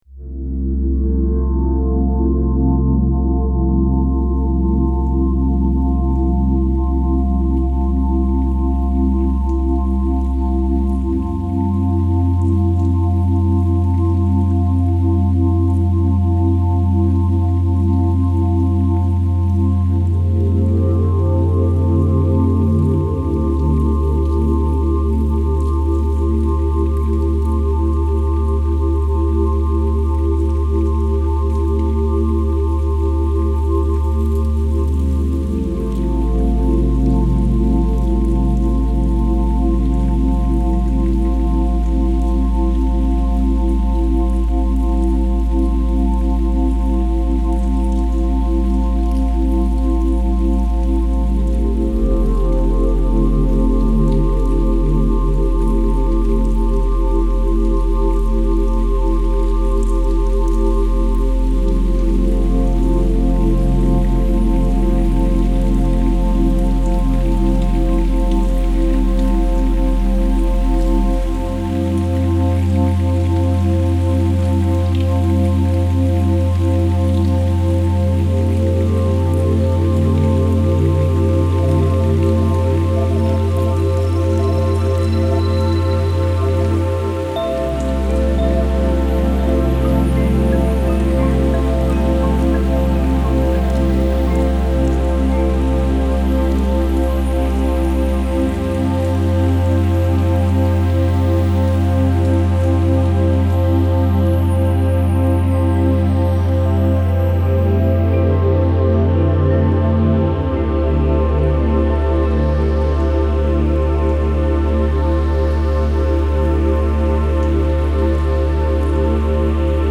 A calming 10-minute Reiki Energy Reset audio meditation Gentle guidance to release stress and restore balance.